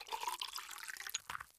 PixelPerfectionCE/assets/minecraft/sounds/item/bottle/fill1.ogg at ca8d4aeecf25d6a4cc299228cb4a1ef6ff41196e
fill1.ogg